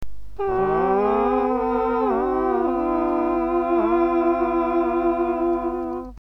sirene.mp3